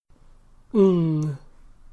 ŋ (velar, nasal, voiced)
ŋ-individual.mp3